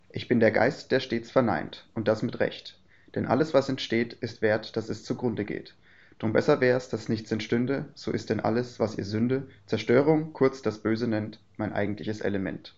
Die Aufnahmen wurden in verschiedenen Abständen aufgenommen.
Ein Hinweis: Die Aufnahmen wurden in einem akustisch gut ausgestatteten Videokonferenzraum aufgenommen.
Abstand 50 cm - Mikrofon vom Sprecher abgewandt
50cm abgewandt Originalaufnahme